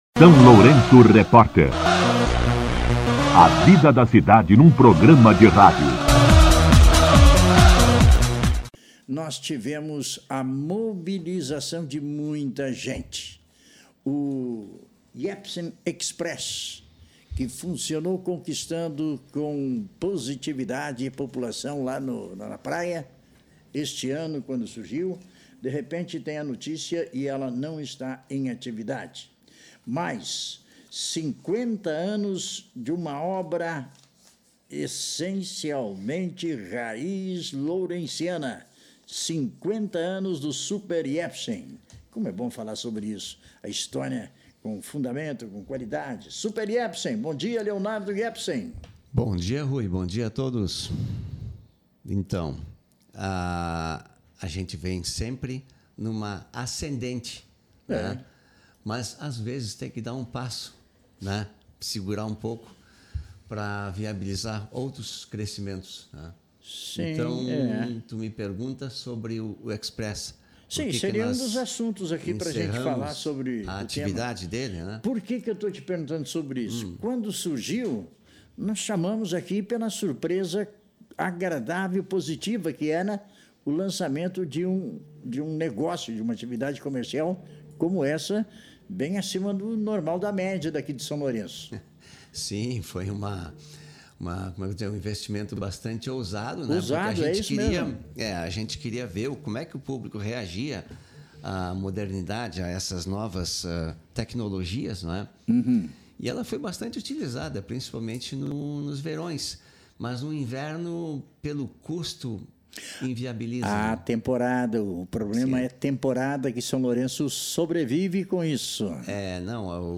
Entrevista com O empresário e proprietário